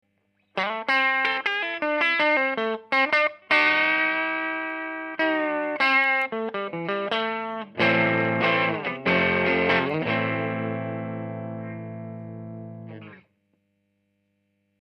meme gratte type télé, meme ampli B4 (EF184+6V6 bias 70%) sans correction de tonalité, meme position du potard de volume de l'ampli, meme micro, a la meme position/distance... il n'y a pas de normalisation des samples, juste une conversion en MP3 codec LAME qui compresse un peu j'en suis désolé.
sample 2 - transfo ultra-bas de gamme PP 10W 4k-8r modifé en SE par l'ajout d'un entrefer primaire 1.3H
Personnellement, les 1 et 2 , je les trouve très "noisy", handicap pour le son clean.